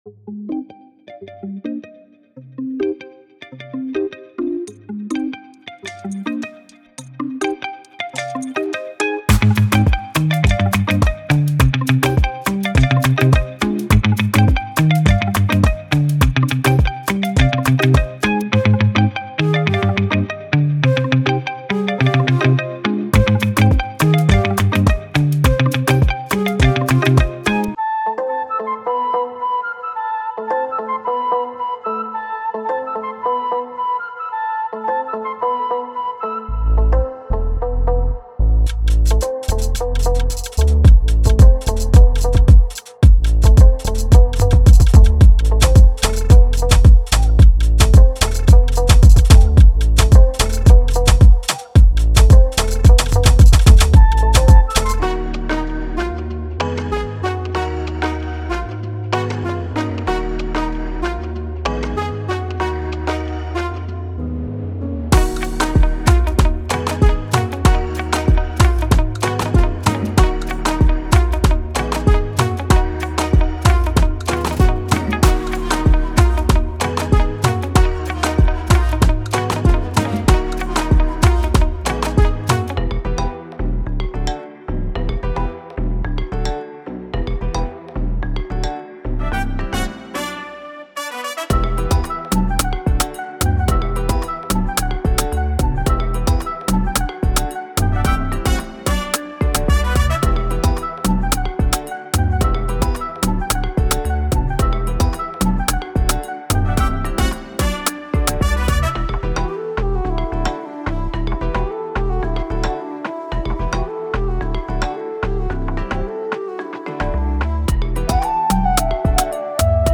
Trap, RnB, Lo-Fi, Soul, Pop, and Drill
Demo
genres like Trap, RnB, Lo-Fi, Soul, Pop, and Drill
• 200+ Vocals
• 148 Drums
• 84 Melodic Samples